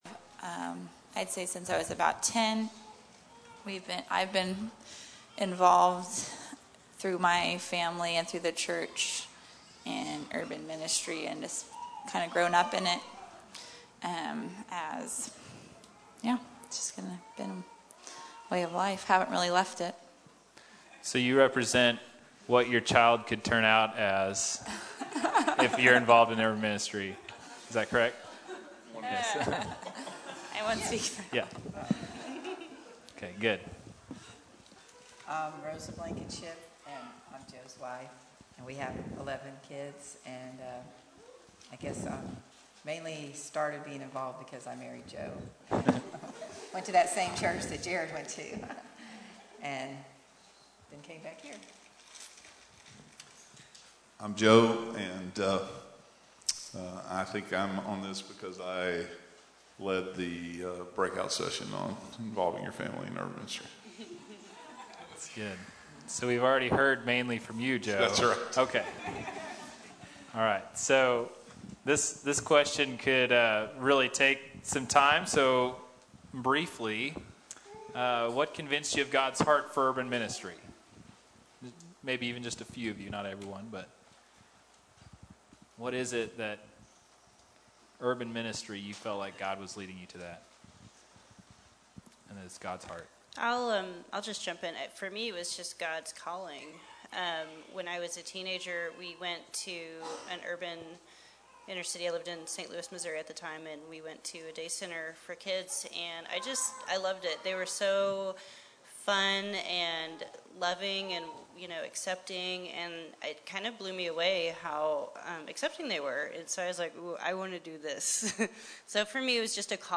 Involving Your Family in Urban Ministry – Panel
Urban Ministry Conference